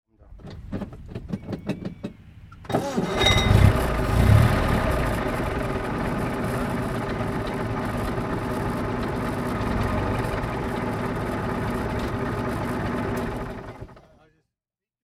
Lancia Lambda Series 8A (1928) - V-4 engine
Lancia Lambda VIII Serie Torpedo Lungo (1928) - Starten und Leerlauf
Lancia_Lambda_1928.mp3